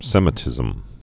(sĕmĭ-tĭzəm)